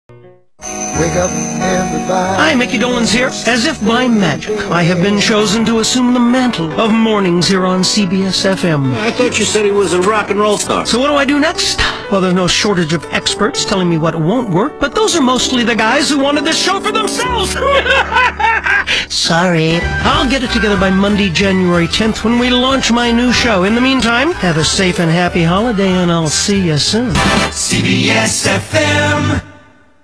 Click to hear one of the promos Micky cut for the station.
micky wcbs promo dec2004.wma